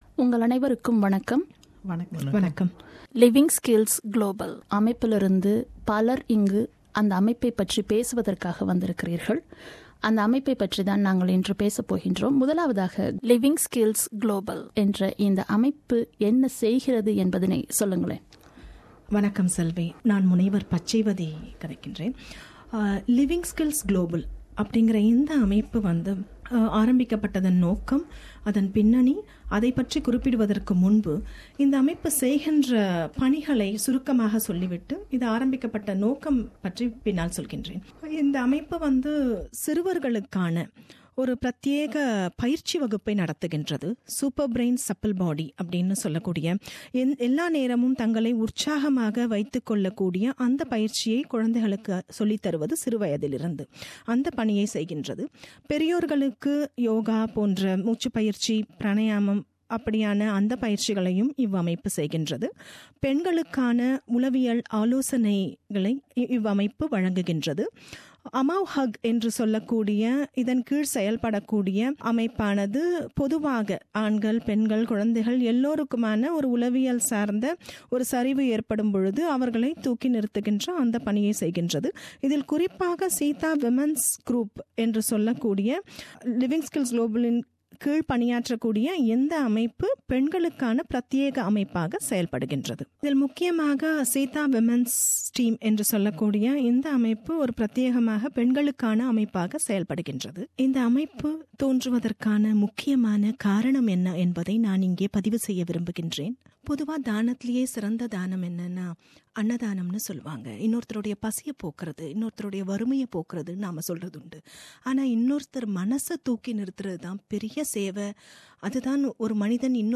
"Living Skills Global" is an Australian registered, non - religious and not- for profit incorporated organisation, and the members consists of professionals from the fields of healthcare, legal, social welfare and education. This interview brings you all details about "Living Skills Global"